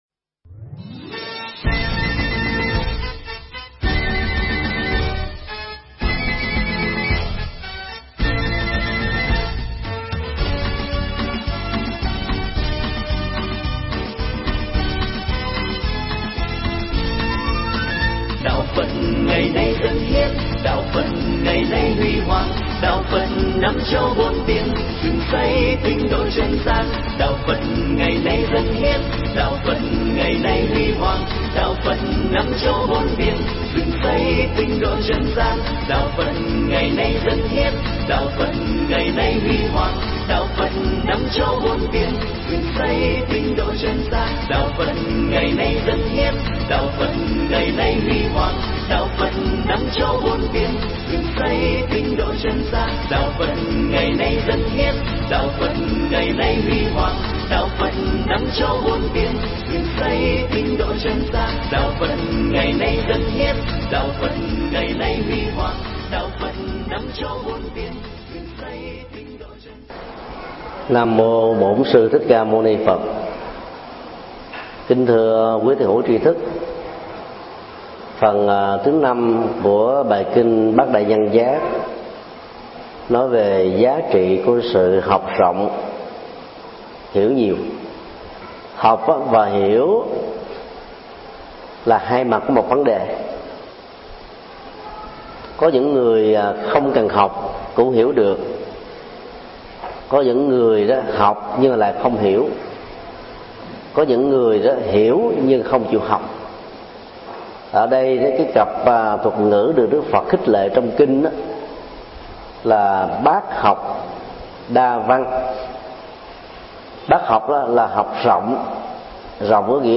Tải mp3 pháp thoại Bát Đại Nhân Giác 05: Học rộng, hiểu nhiều do thầy Thích Nhật Từ giảng tại chùa Xá Lợi ngày 04 tháng 07 năm 2010